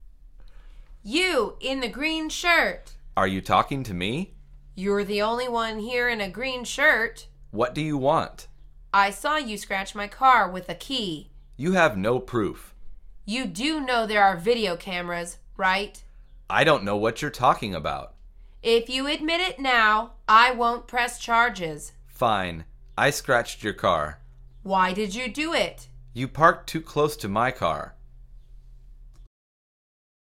مجموعه مکالمات ساده و آسان انگلیسی – درس شماره ششم از فصل رانندگی: خراشیدن ماشین